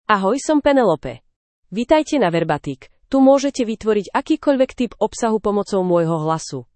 PenelopeFemale Slovak AI voice
Penelope is a female AI voice for Slovak (Slovakia).
Voice sample
Female
Penelope delivers clear pronunciation with authentic Slovakia Slovak intonation, making your content sound professionally produced.